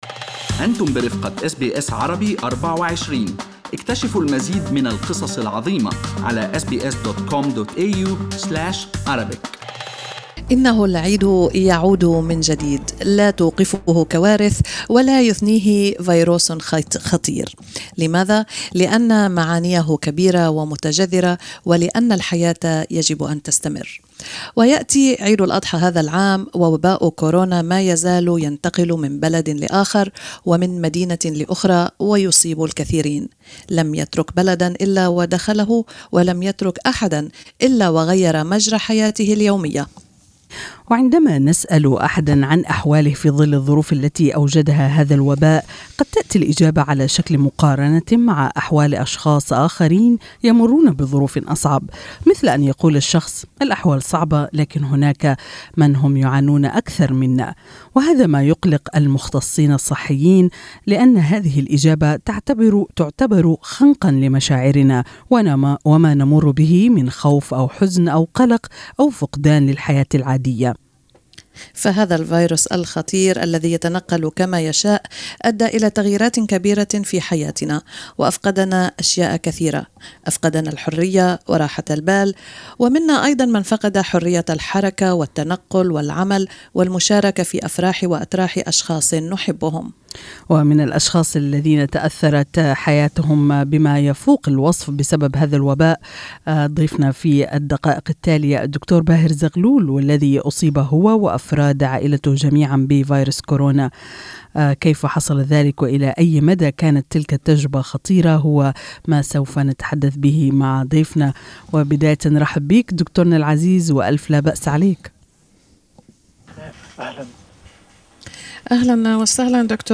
رب أسرة يتحدث عن معاناة أسرته التي أصيبت بفيروس كورونا